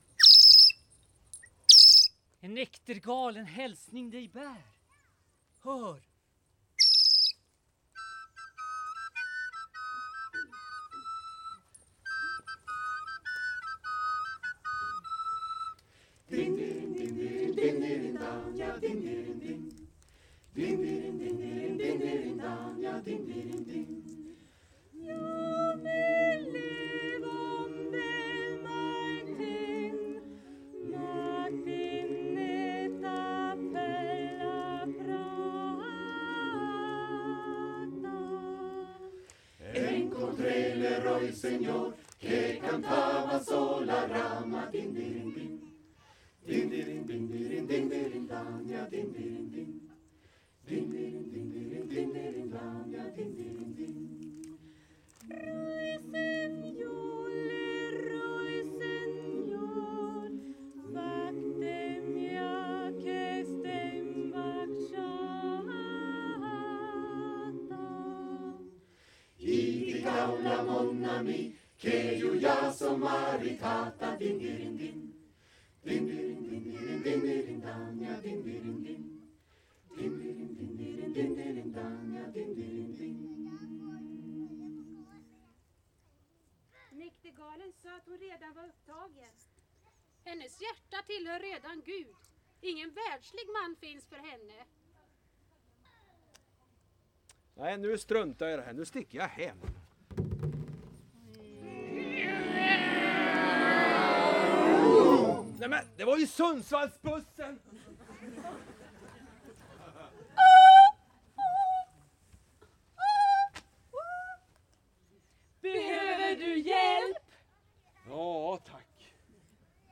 Här hör du slutet av sundsvallskören Mousikés historiska spel om medeltid och pilgrimsvandringar – från Selånger, över Stöde till Nidaros, eller Trondheim som vi säger idag. De framförde sin berättelse i en varm och tät sommarstämning vid Murbergskyrkan 1990.